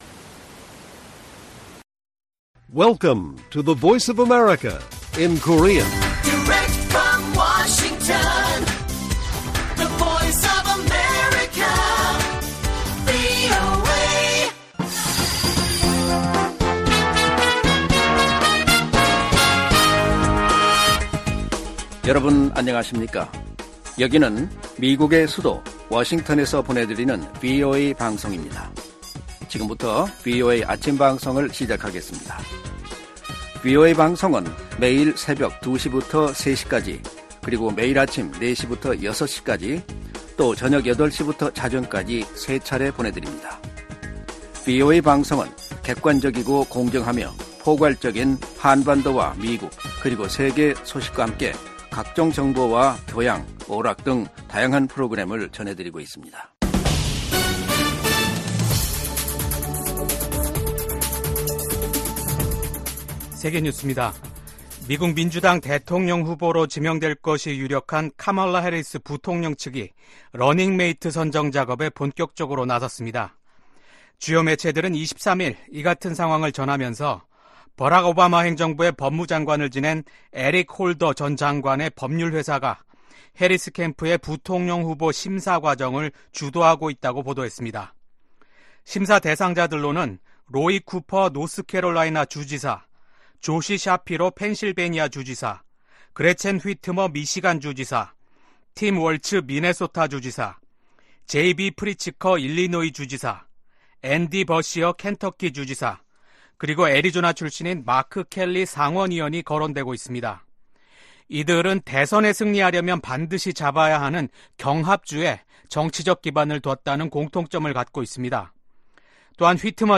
세계 뉴스와 함께 미국의 모든 것을 소개하는 '생방송 여기는 워싱턴입니다', 2024년 7월 25일 아침 방송입니다. '지구촌 오늘'에서는 베냐민 네타냐후 이스라엘 총리가 24일 미국 상하원 합동회의에서 진행할 연설 관련 소식 전해드리고 '아메리카 나우'에서는 카멀라 해리스 부통령이 경합주에서 첫 대선 유세를 하며 공화당 대선 후보인 도널드 트럼프 전 대통령을 집중 공격한 소식 전해드립니다.